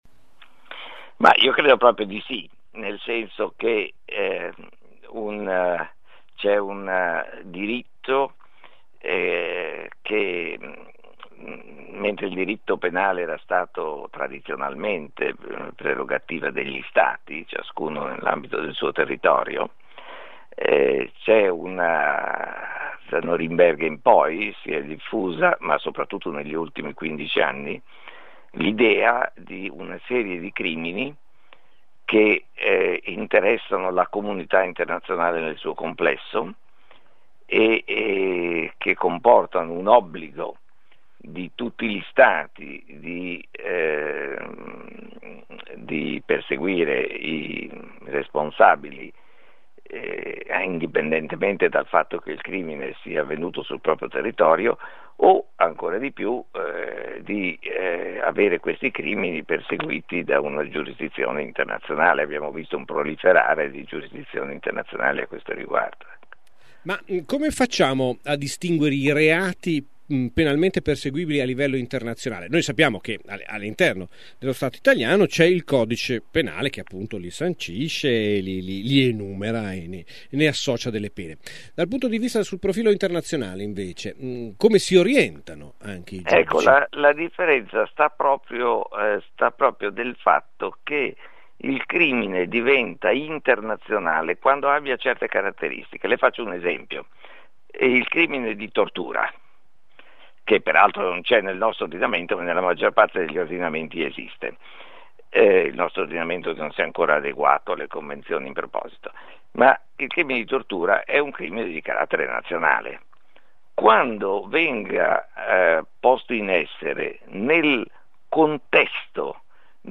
Verso il diritto penale internazionale. Intervista a Fausto Pocar